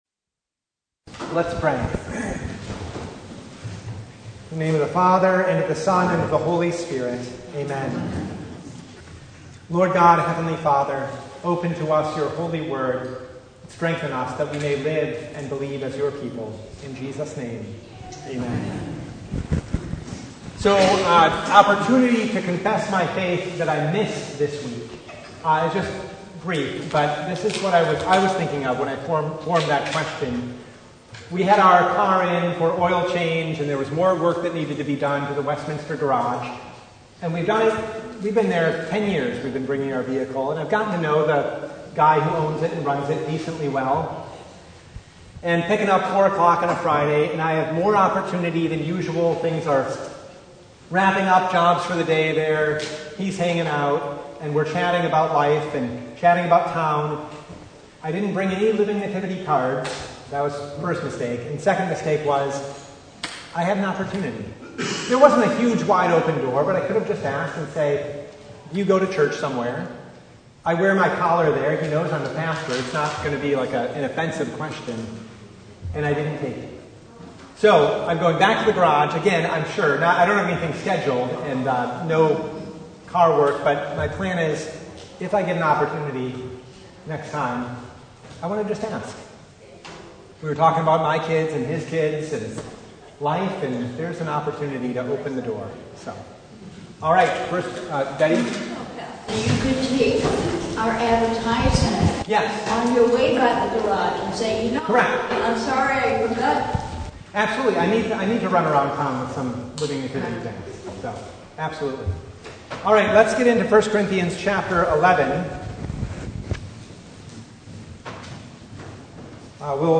1 Corinthians 11:27-34 Service Type: Bible Hour Topics: Bible Study « Resurrection or Not?